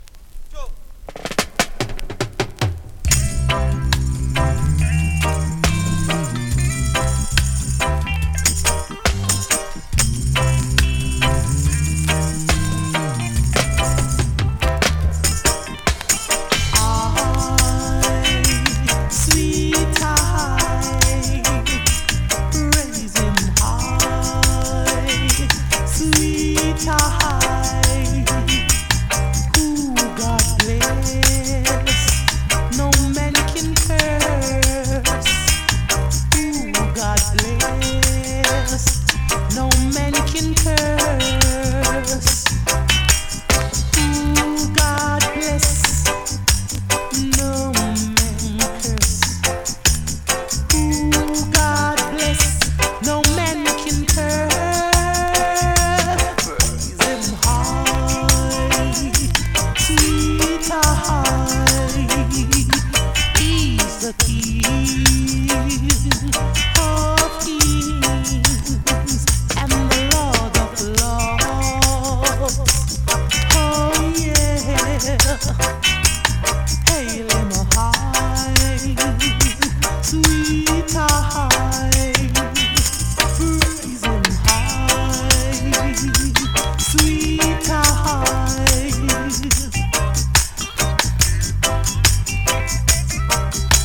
コンディションVG++(少しノイズ)
スリキズ、ノイズ比較的少なめで